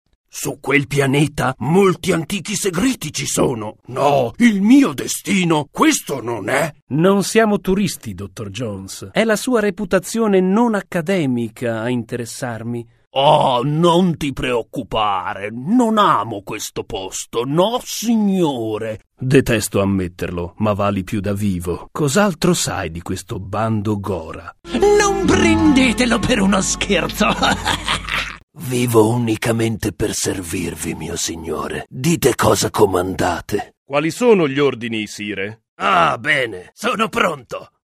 Sprecher italienisch.
Sprechprobe: eLearning (Muttersprache):